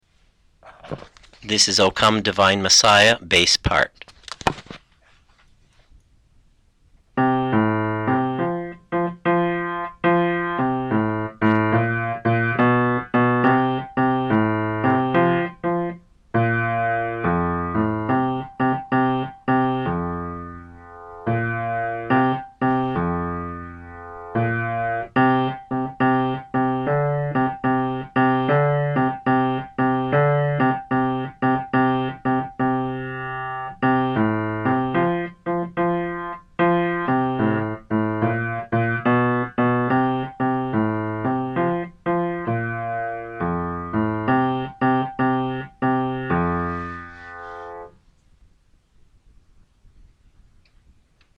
O Come Divine Messiah - Alto 2012-10-20 Choir